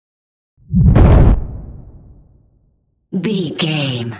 Dramatic deep whoosh to hit trailer
Sound Effects
Atonal
dark
intense
woosh to hit